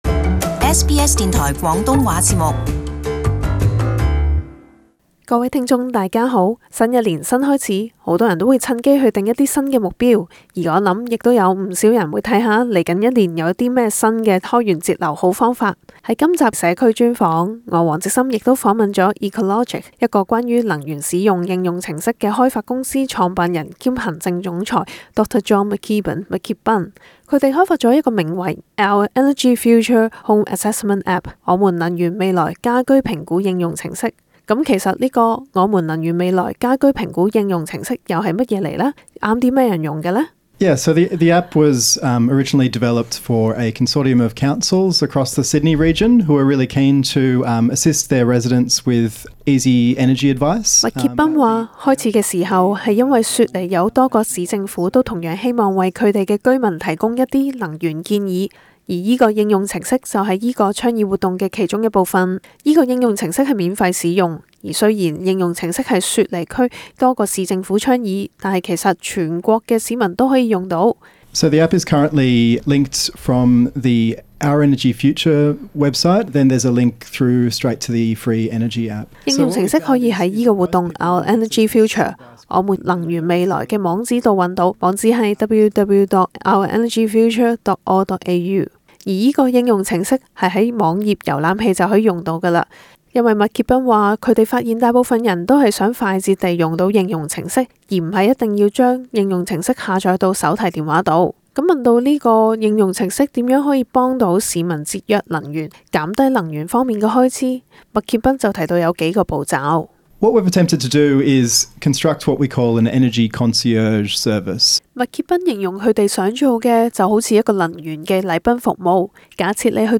【社區專訪】雪梨有市政府推家居能源效益評估應用程式